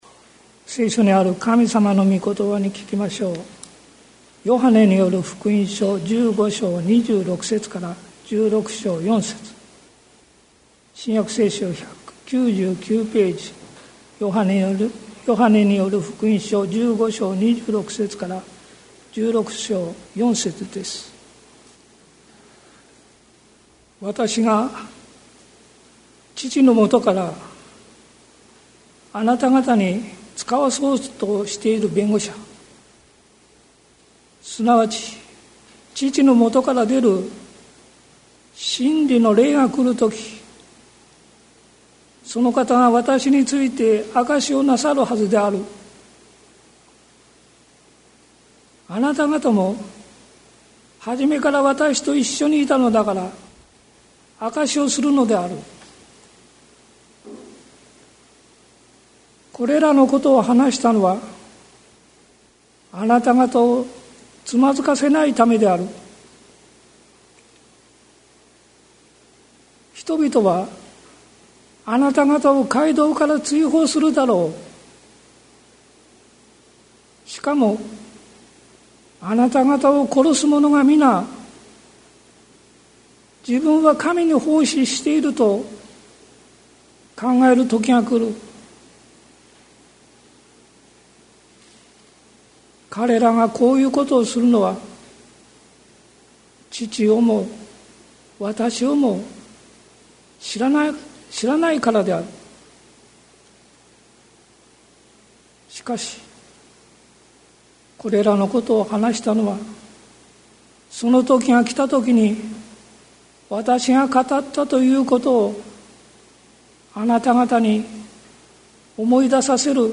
2023年08月06日朝の礼拝「思い出そう、キリストの言葉」関キリスト教会
説教アーカイブ。